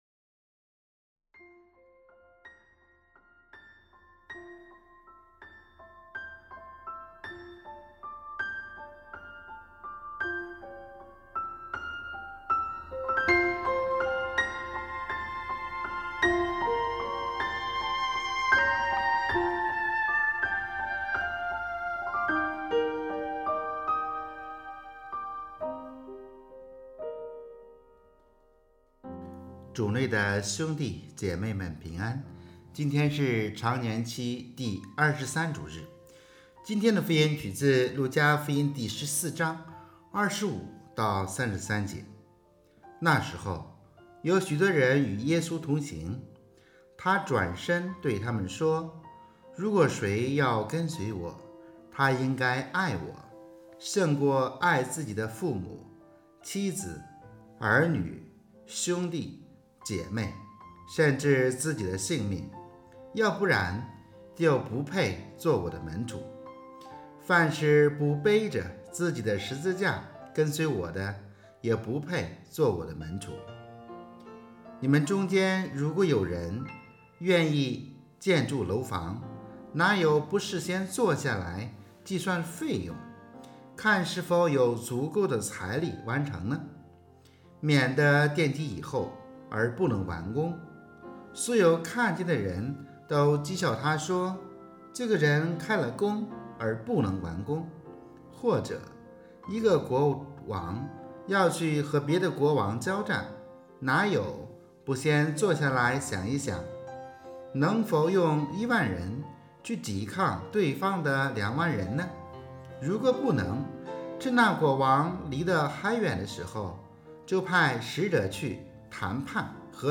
【主日证道】| 舍得方为门徒（丙-常23主日）